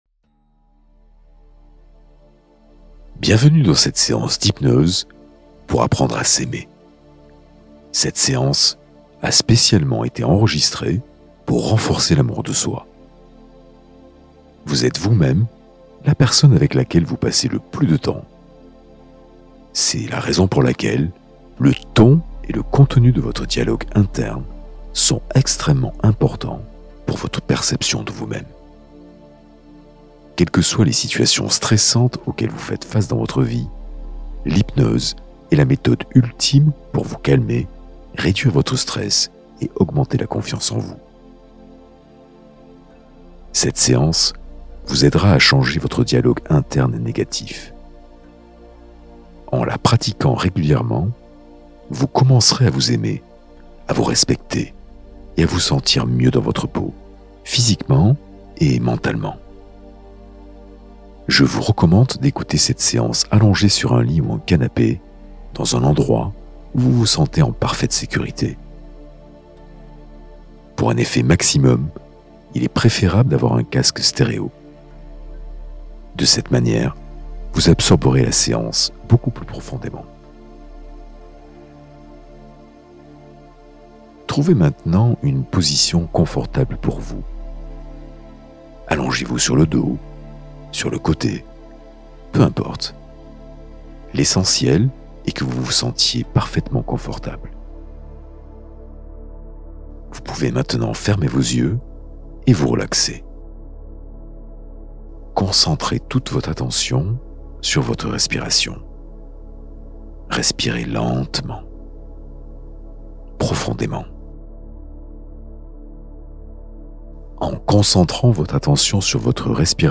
Hypnose pour manifester ses rêves